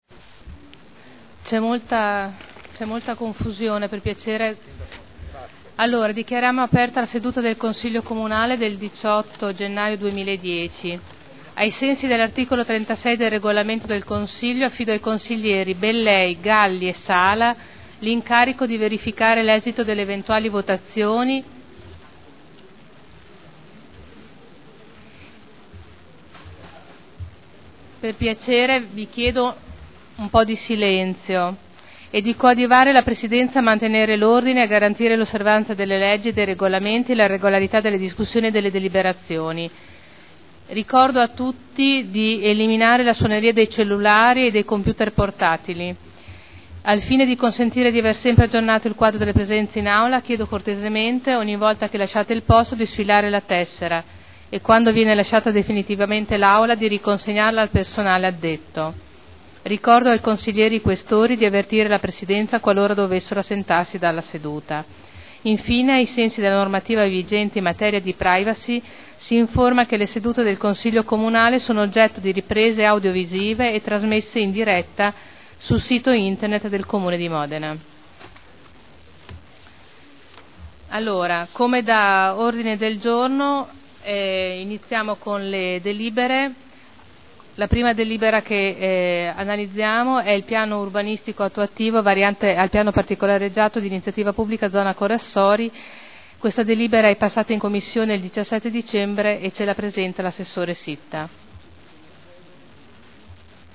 Apertura del Consiglio Comunale